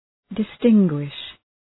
Προφορά
{dı’stıŋgwıʃ} (Ρήμα) ● διακρίνω ● ξεχωρίζω